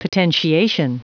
Prononciation du mot potentiation en anglais (fichier audio)
Prononciation du mot : potentiation